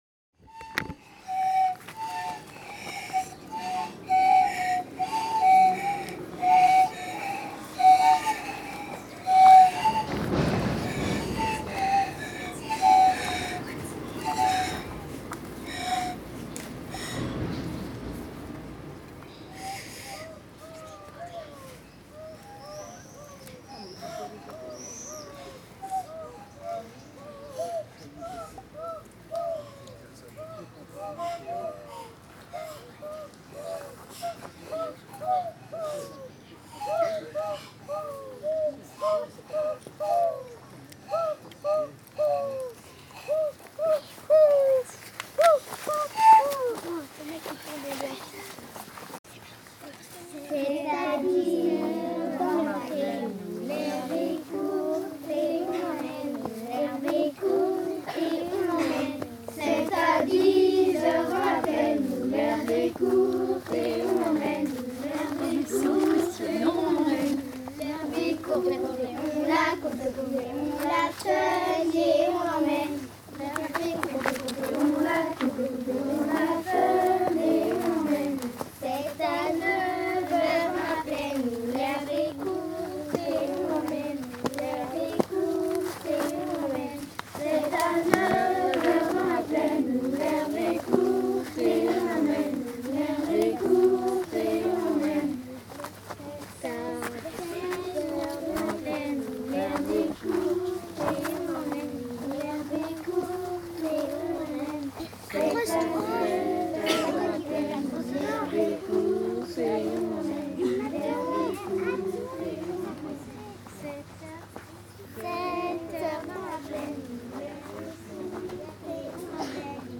01_enfants.mp3